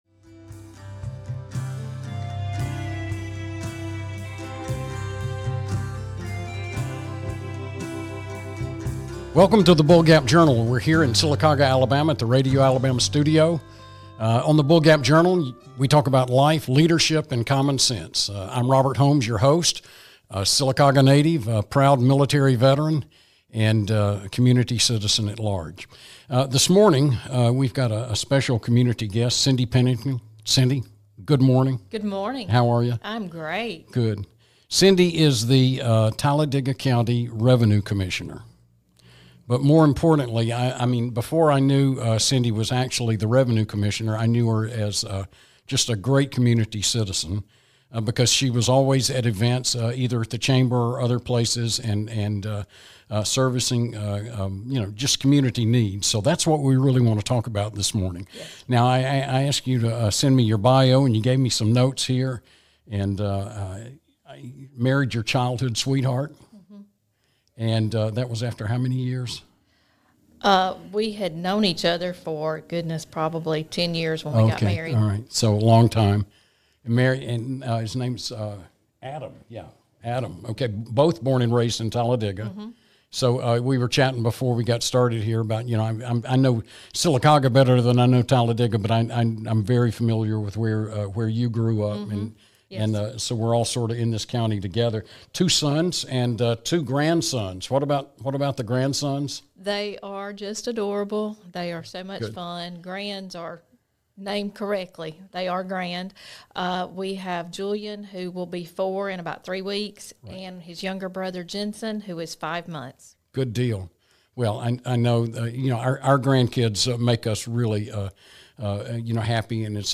as the two talk about all things pertaining to Life, Leadership, and Common Sense.